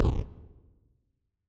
ShotFiring.wav